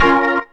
B3 BMIN 1.wav